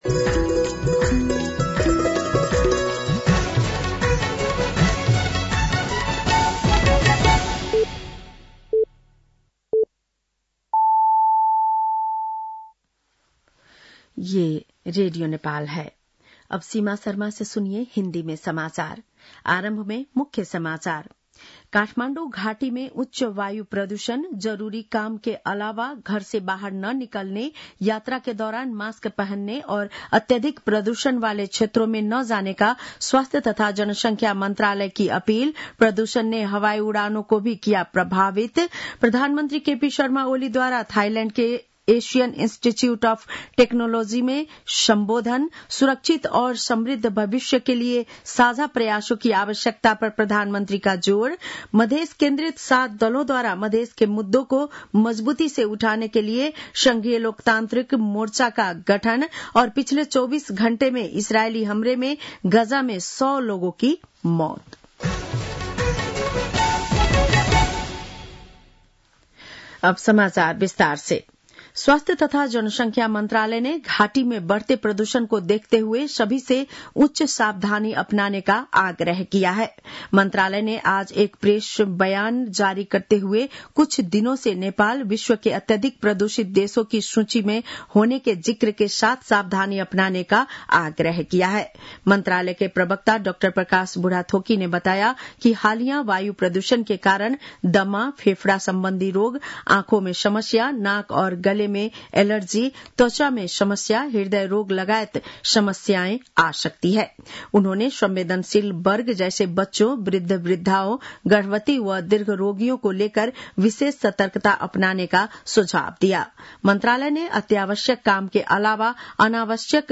बेलुकी १० बजेको हिन्दी समाचार : २१ चैत , २०८१